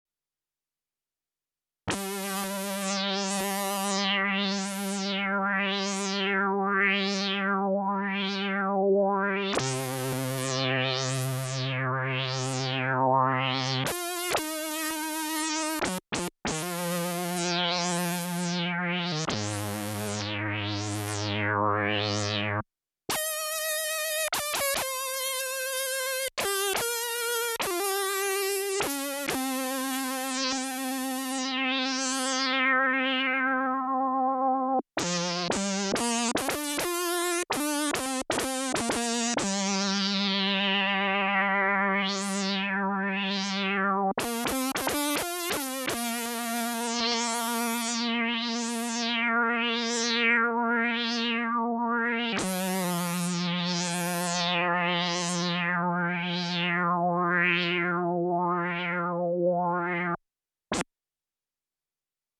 Phucked Lead – Future Impact Program Database
QM+Phucked+Lead.mp3